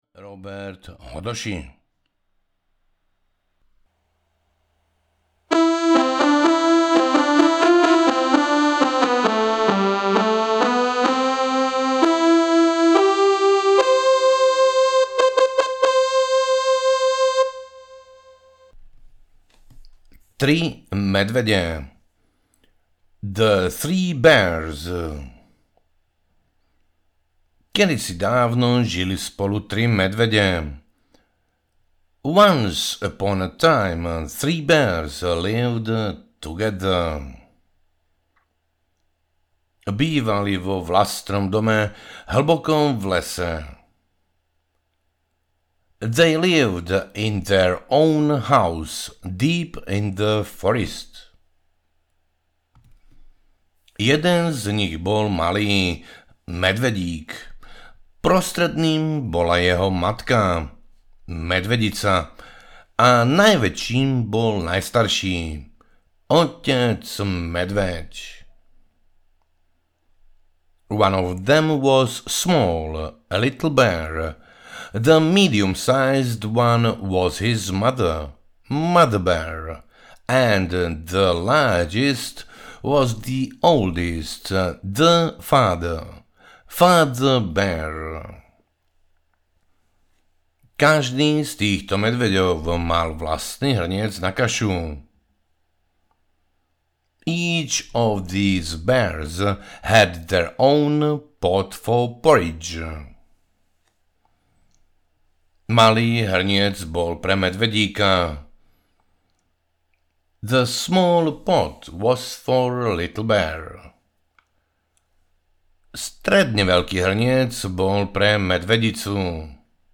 Poviedky a vety 1, Anglicky, Slovensky audiokniha
Ukázka z knihy
poviedky-a-vety-1-anglicky-slovensky-audiokniha